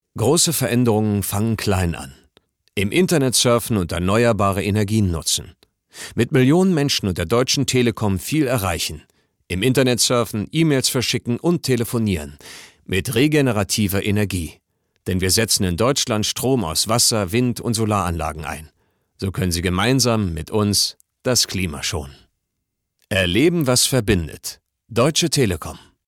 plakativ
Mittel plus (35-65)
Comment (Kommentar)